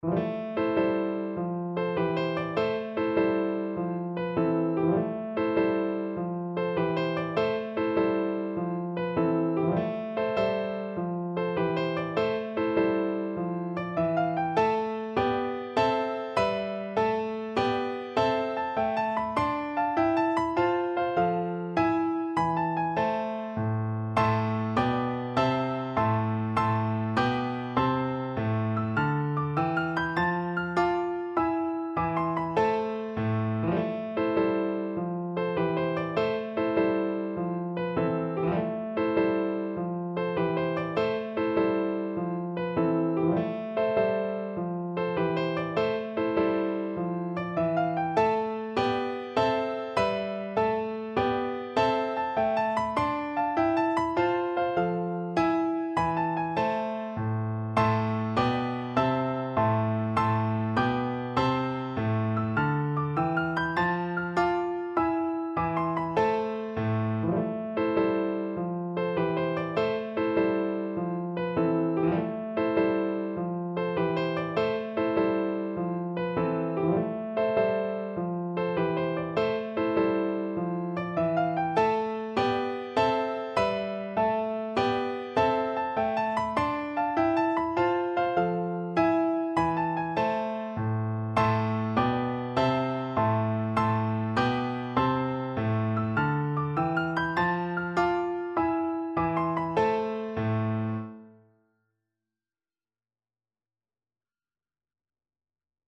Christmas
A minor (Sounding Pitch) (View more A minor Music for Oboe )
Steady March . = c.100
6/8 (View more 6/8 Music)